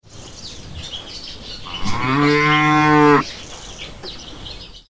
Cow Mooing Inside Barn Sound Effect
Ambient sound of a barn interior with swallows chirping and a cow mooing once. Authentic rural farm atmosphere, ideal for sound design and background ambience. Farm animal sounds.
Cow-mooing-inside-barn-sound-effect.mp3